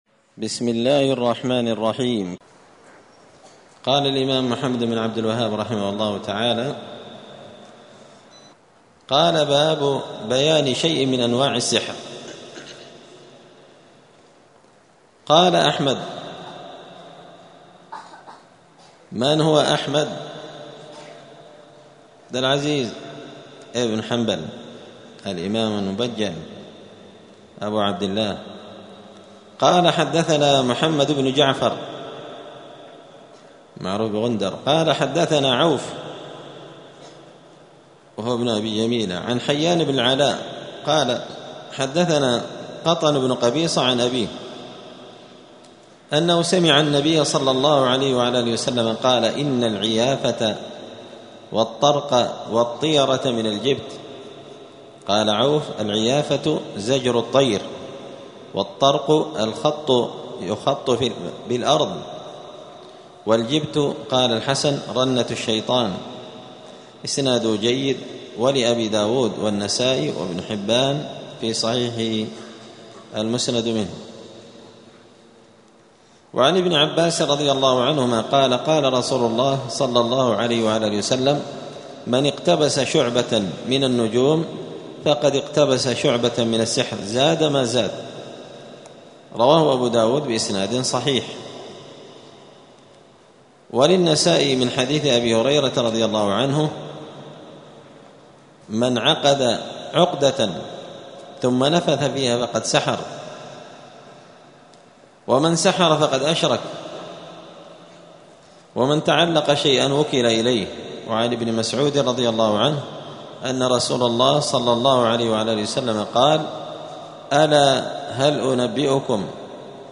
دار الحديث السلفية بمسجد الفرقان قشن المهرة اليمن
*الدرس السبعون (70) {باب بيان شيء من أنواع السحر}*